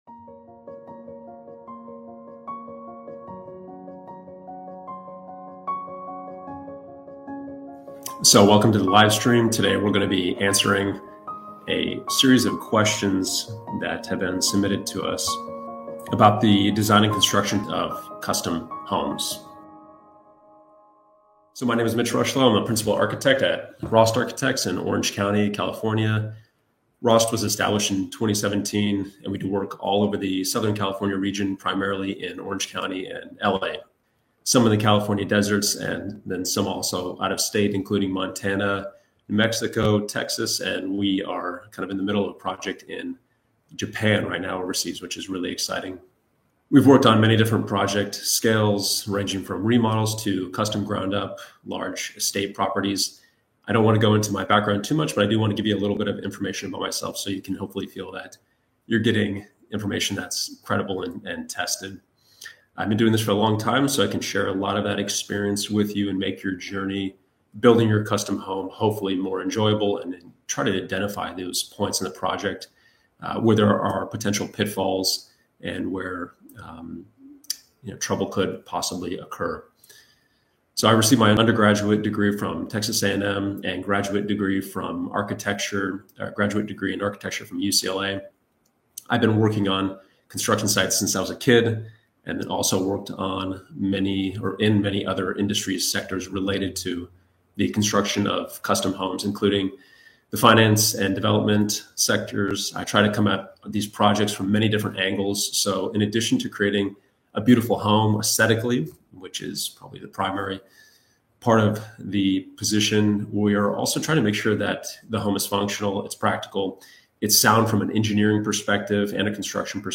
ROST Talks Live Series - Custom Home Q&A, Architect Answers Design and Construction Questions